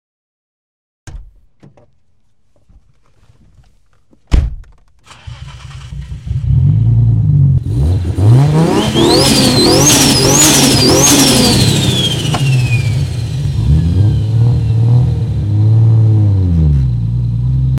R33 GTR sound check 🔈 sound effects free download